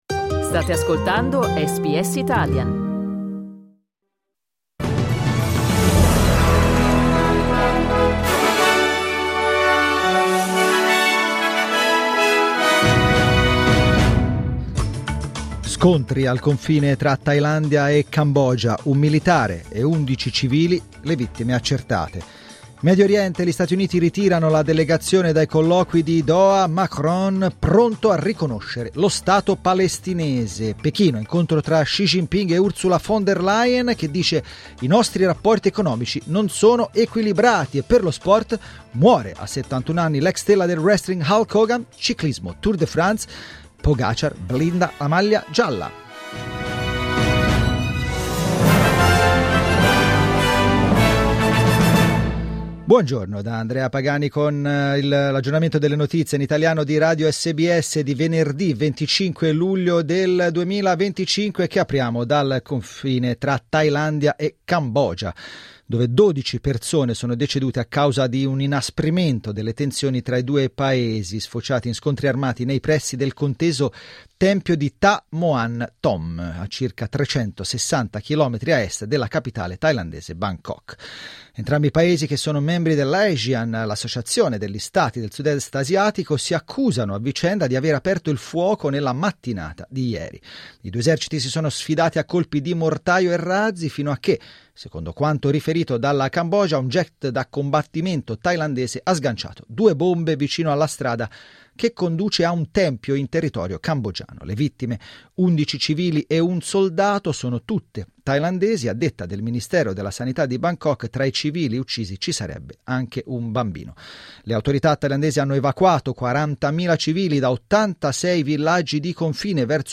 Giornale radio venerdì 25 luglio 2025
Il notiziario di SBS in italiano.